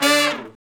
Index of /90_sSampleCDs/Roland L-CD702/VOL-2/BRS_R&R Horns/BRS_R&R Falls